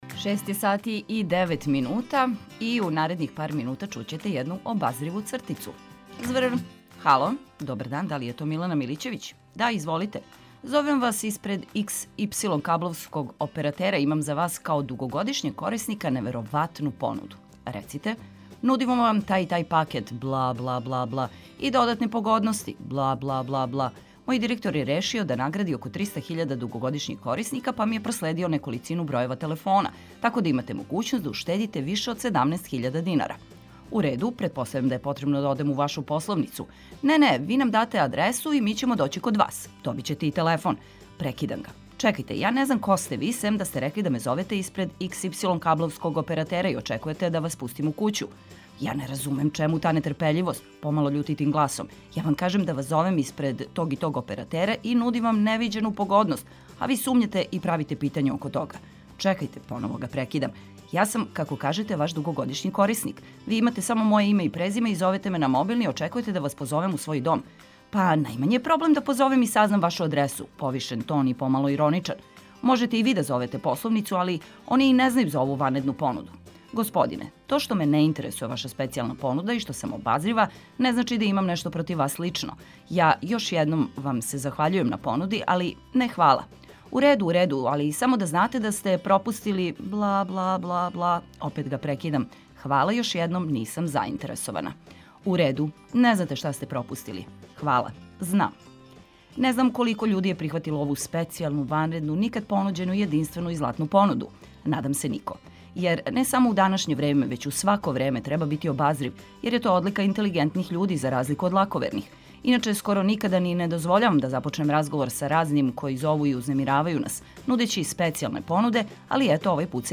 Нека буђење буде задовољство у друштву ведрих пријатеља са радија.